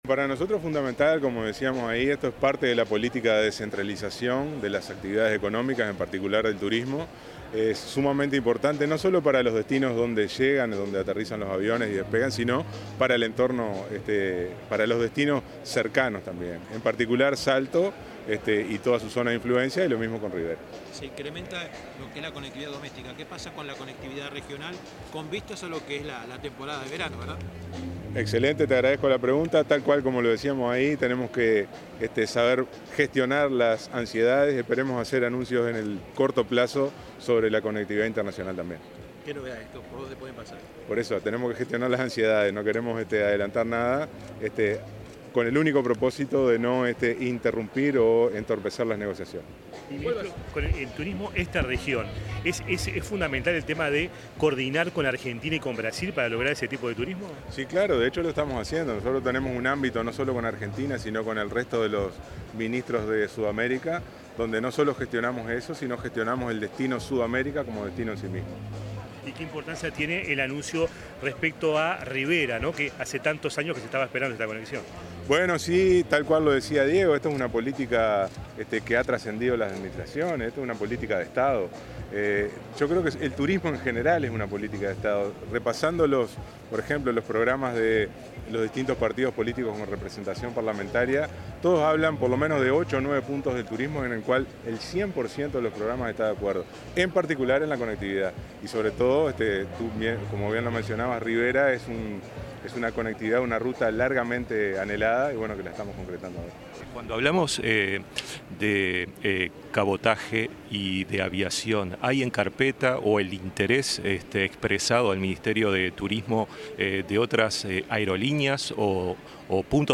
Declaraciones del ministro de Turismo, Pablo Menoni
El ministro de Turismo, Pablo Menoni, brindó declaraciones a los medios de prensa, luego de la presentación de una nueva frecuencia aérea entre